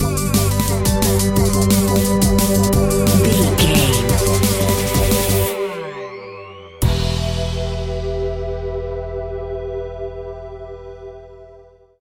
Aeolian/Minor
Fast
aggressive
powerful
uplifting
futuristic
hypnotic
industrial
dreamy
drum machine
synthesiser
electronic
sub bass
synth leads
synth bass